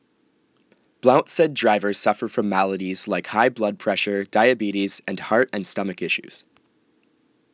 American_English_Speech_Data_by_Mobile_Phone_Reading